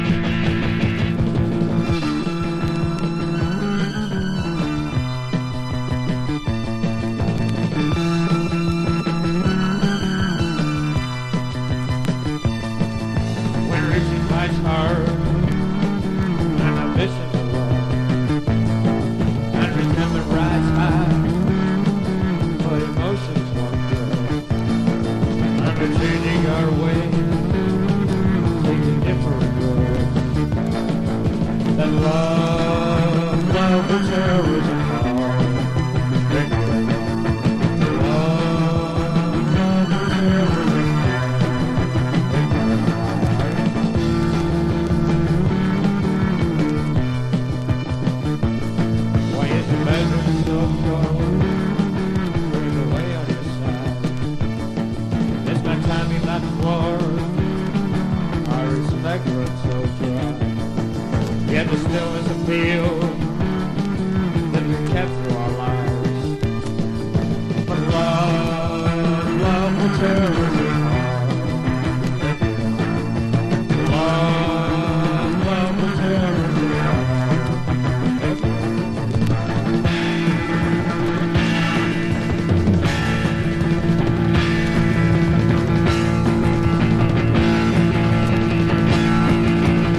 演奏ミスも多いですが当時の勢いが感じられる1枚。